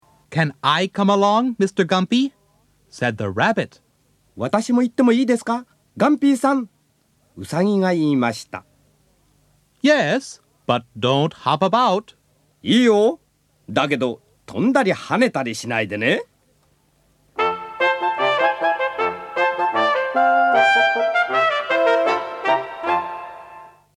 コアの英日教材は英語と日本語が交互に語られ、文ごとの意味がつかめます。 楽しくイメージ豊かな音楽が、お子様方の想像力をふくらませ、 英語の物語と生きた言葉がストレートに伝わってきます。 親しみがわく物語教材 英語の世界への入口を、物語世界で体験してください。